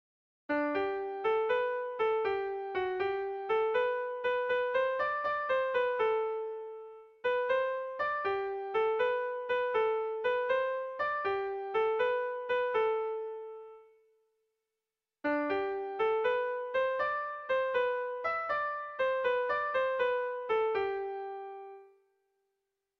Erlijiozkoa
ABDE